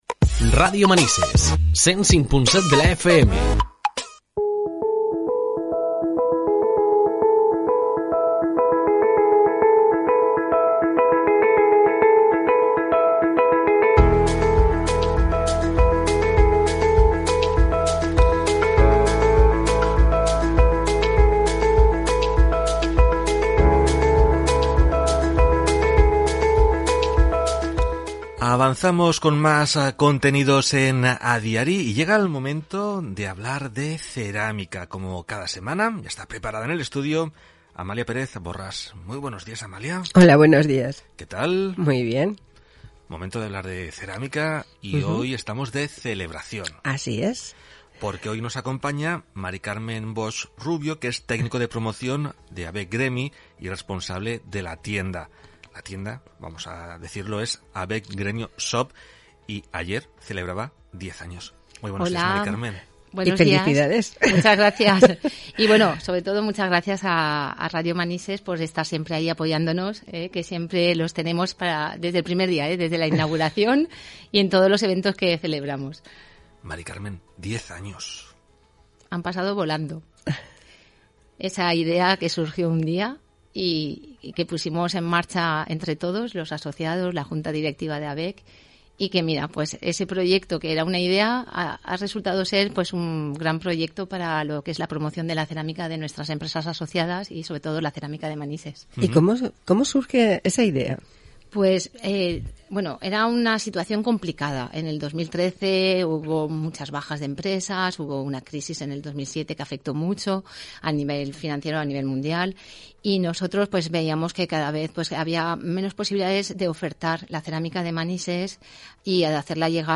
Hemos estado en la radio